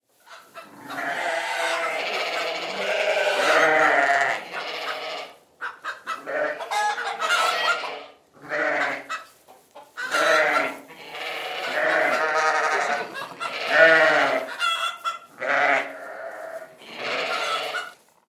Голоса животных в сельском хозяйстве